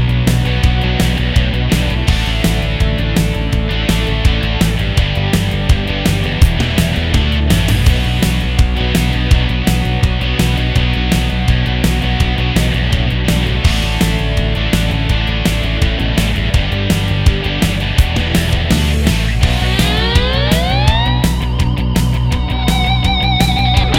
Live Rock 3:47 Buy £1.50